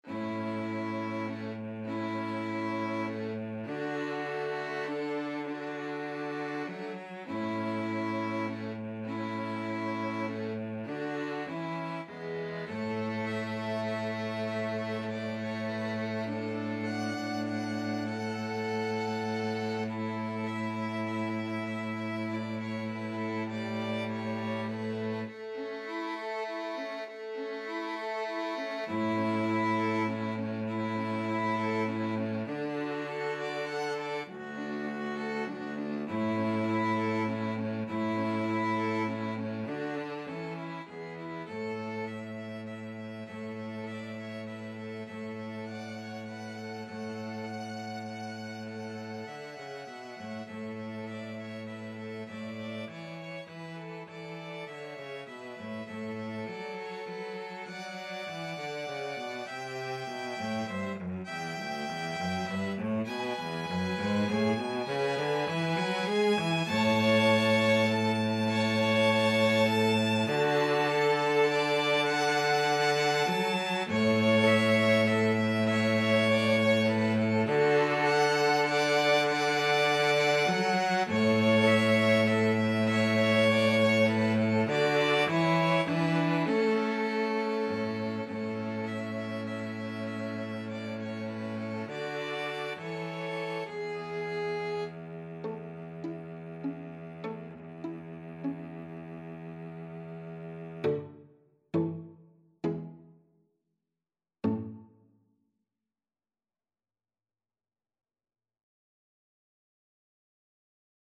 Violin 1Violin 2Cello
3/4 (View more 3/4 Music)
A major (Sounding Pitch) (View more A major Music for 2-Violins-Cello )
Gently = c. 100
Traditional (View more Traditional 2-Violins-Cello Music)